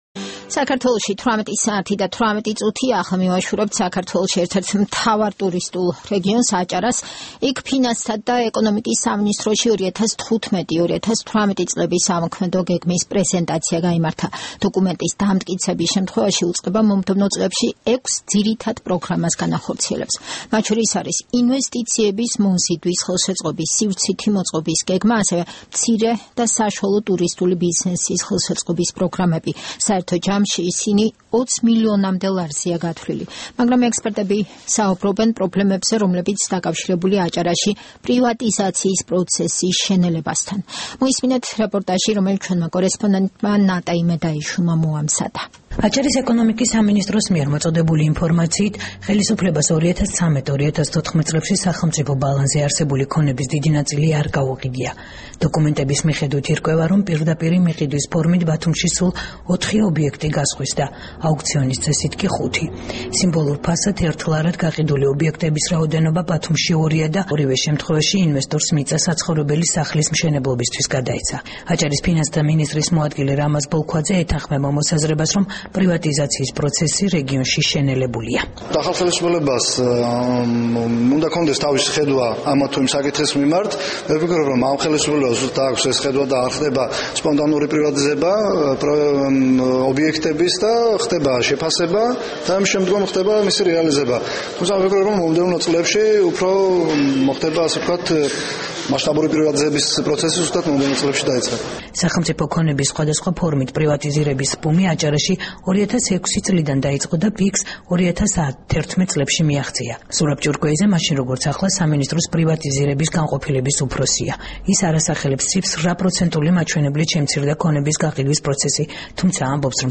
რეპორტაჟი ბათუმიდან